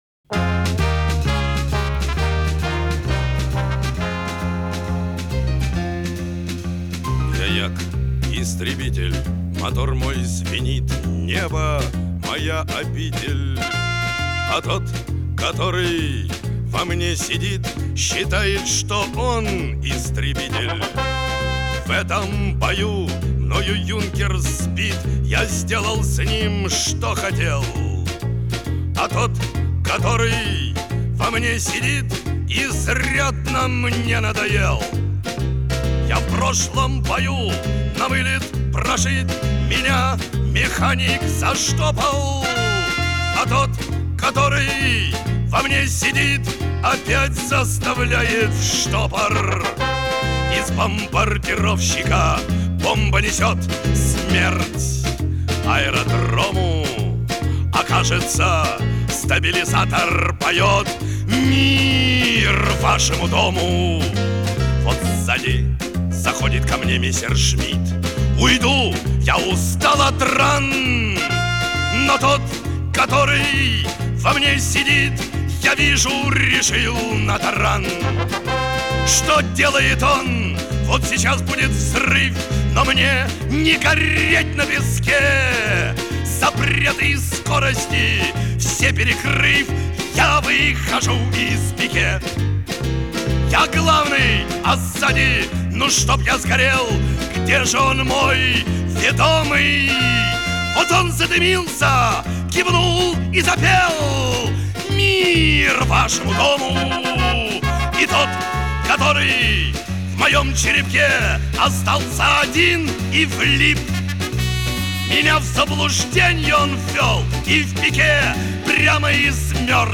Ему страшно, и  он визжит, как резаный поросёнок!